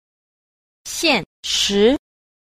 9. 現實 – xiànshí – hiện thực